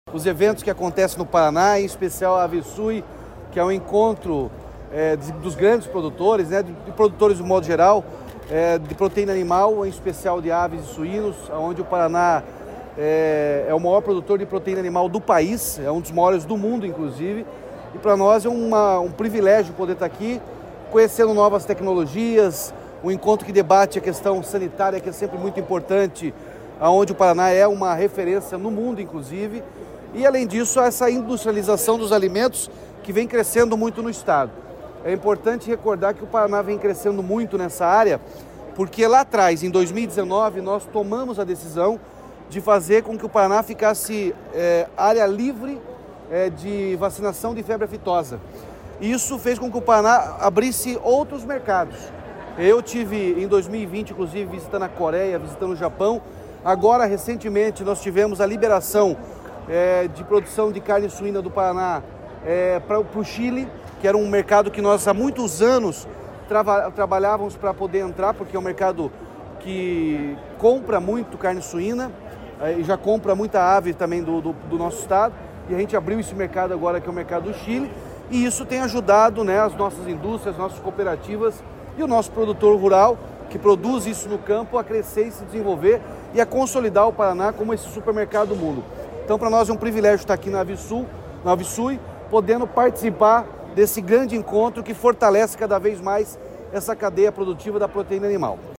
Sonora do governador Ratinho Junior sobre a 24ª edição da AveSui | Governo do Estado do Paraná
Sonora do governador Ratinho Junior sobre a 24ª edição da AveSui